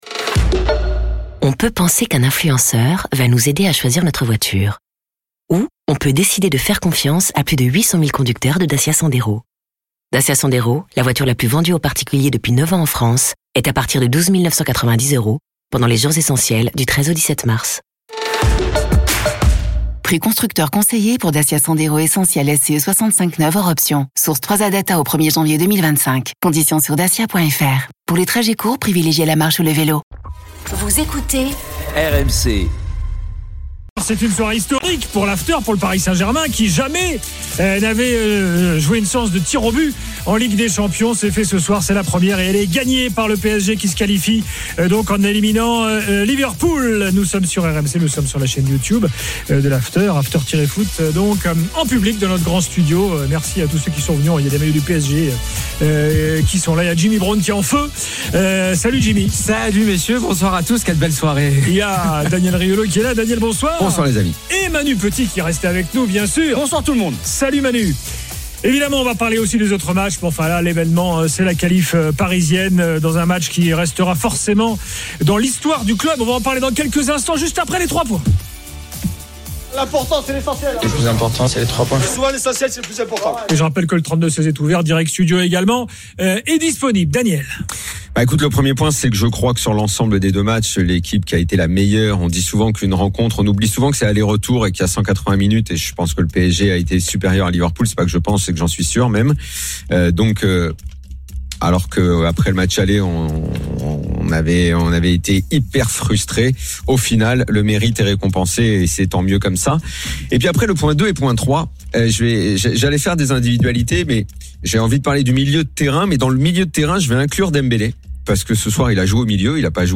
avec les réactions des joueurs et entraîneurs, les conférences de presse d’après-match et les débats animés entre supporters, experts de l’After et auditeurs RMC.
Chaque jour, écoutez le Best-of de l'Afterfoot, sur RMC la radio du Sport !